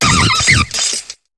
Cri de Vortente dans Pokémon HOME.